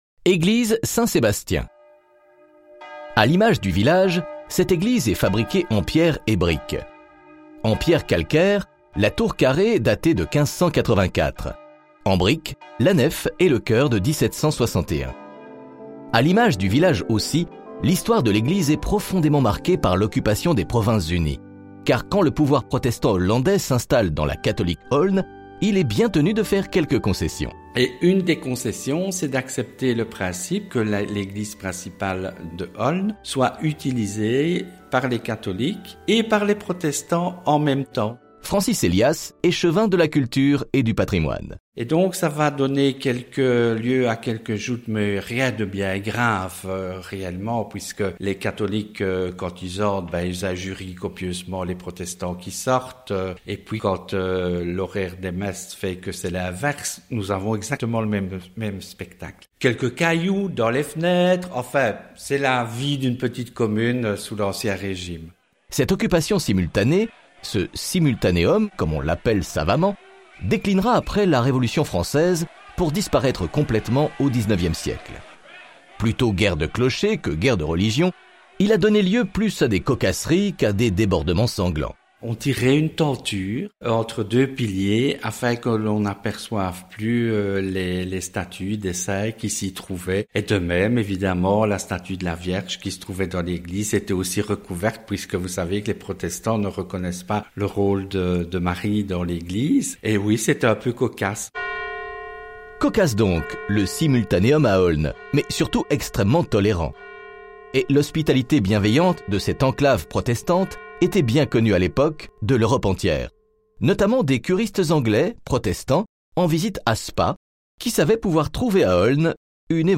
🎧 Découvrez Olne autrement avec l’audioguide (FR). Laissez-vous guider à travers ses ruelles pittoresques, son patrimoine et ses paysages verdoyants, tout en écoutant des anecdotes sur l’histoire locale.